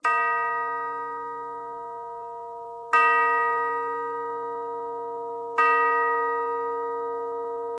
Clock Strikes Three